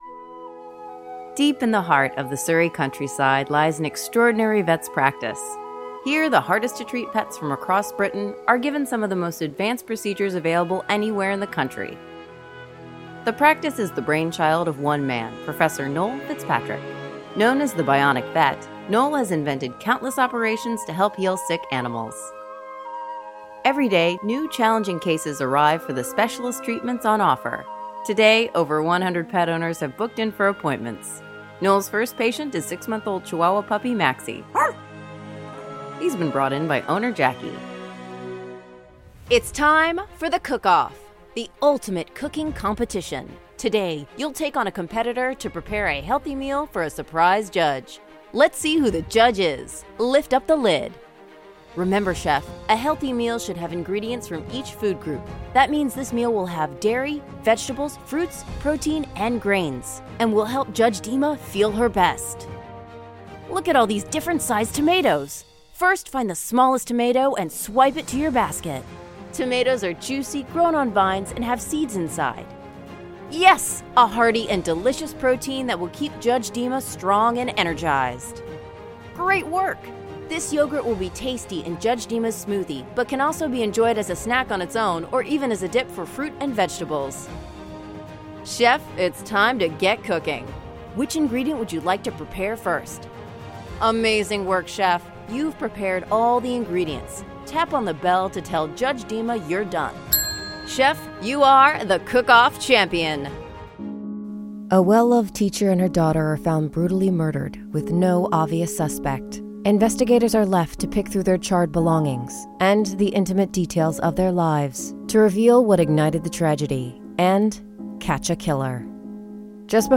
new york : voiceover : commercial : women